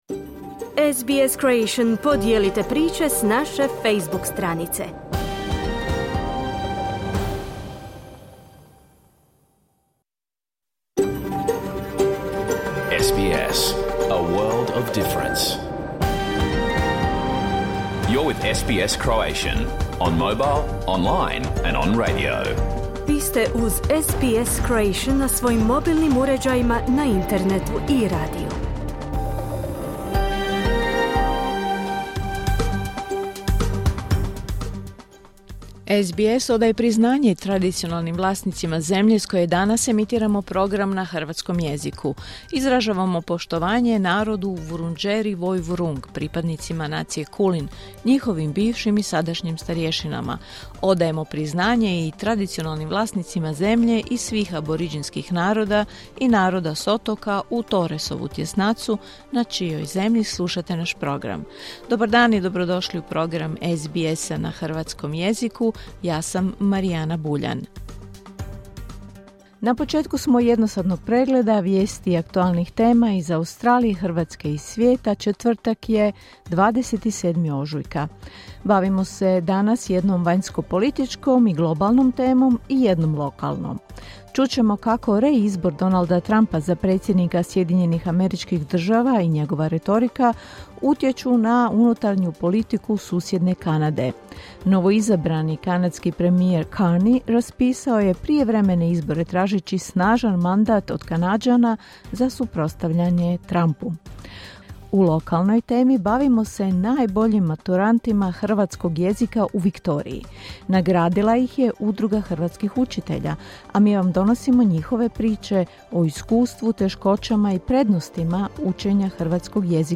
Vijesti, aktualne teme, analize i razgovori iz Australije, Hrvatske i ostatka svijeta.